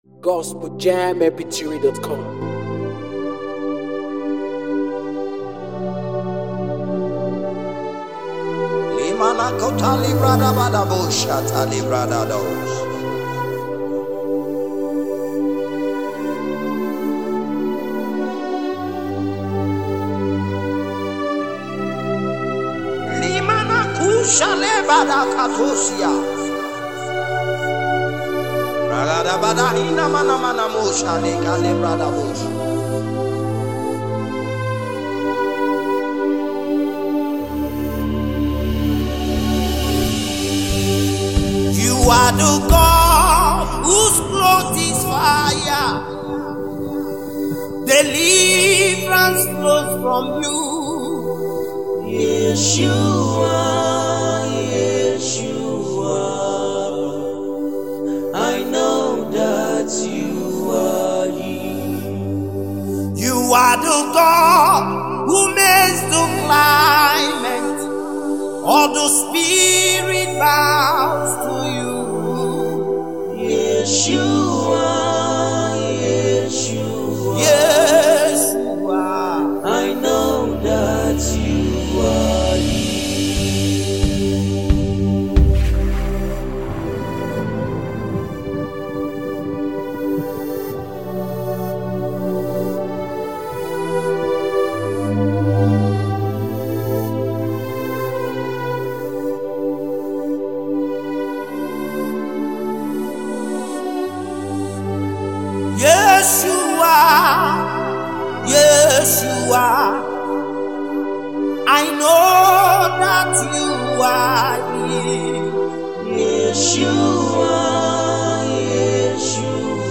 NUMBER 1 AFRICA GOSPEL PROMOTING MEDIA